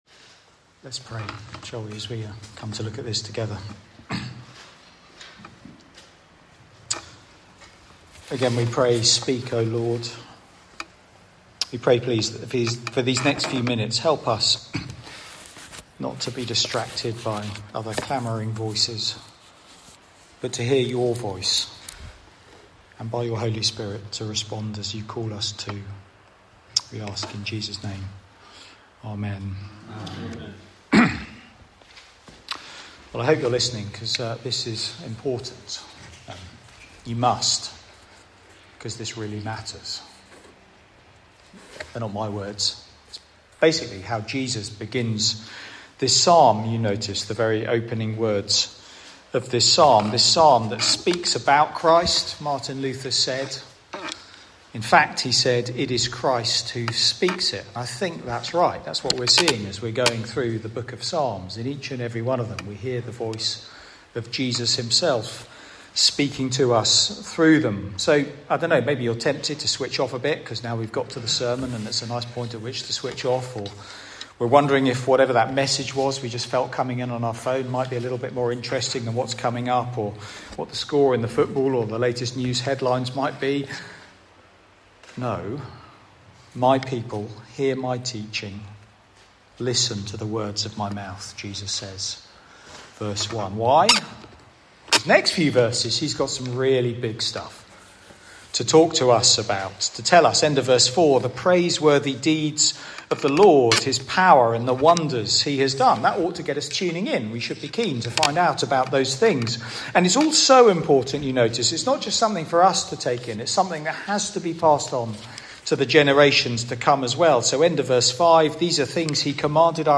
Media for Sunday Evening on Sun 10th Aug 2025 18:00
Theme: Sermon